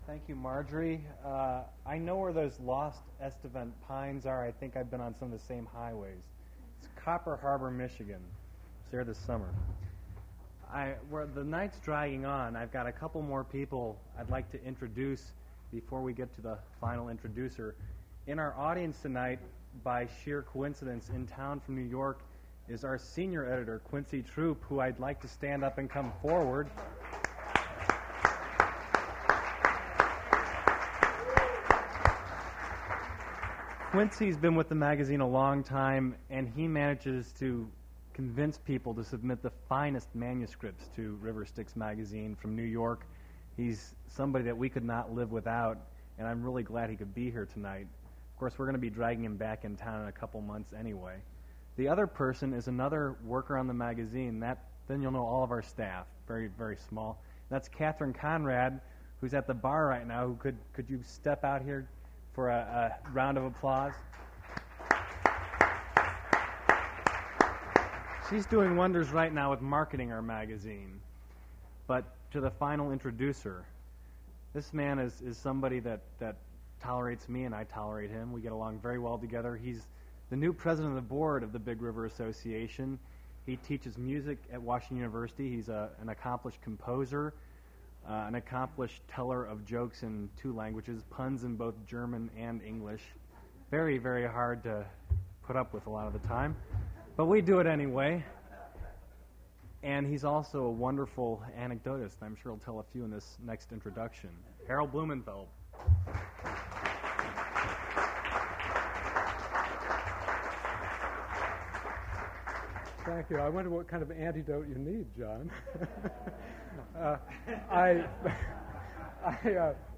Prose reading featuring William Gass
• William Gass prose reading at Duff's Restaurant.
• mp3 edited access file was created from unedited access file which was sourced from preservation WAV file that was generated from original audio cassette.
• Recording cut off mid-sentence